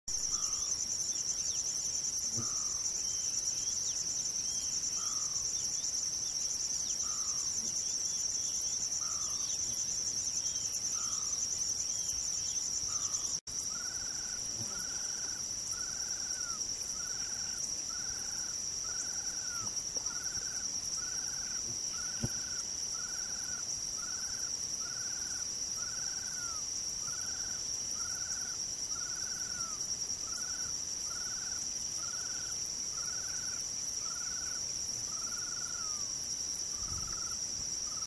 Burrito Grande (Mustelirallus albicollis)
Nombre en inglés: Ash-throated Crake
Fase de la vida: Adulto
Condición: Silvestre
Certeza: Vocalización Grabada